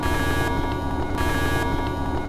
alarm2.wav